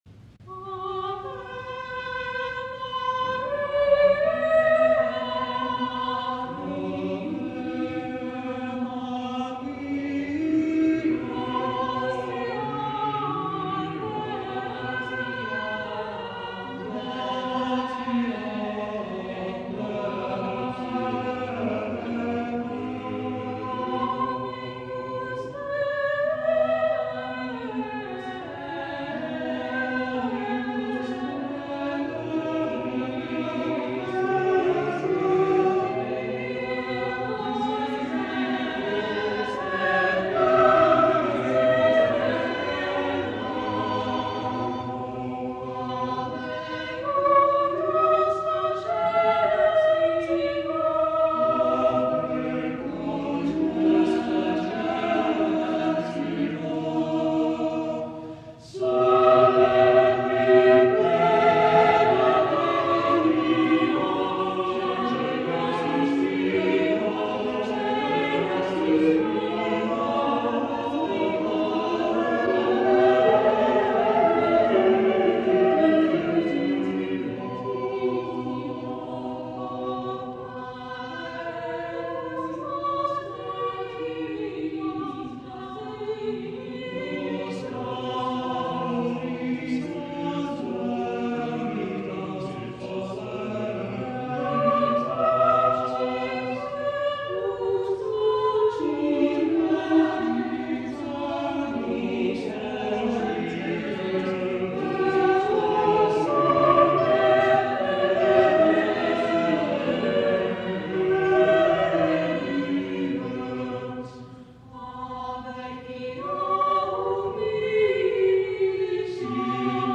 This is the first motet in the first book of motets ever printed, ie.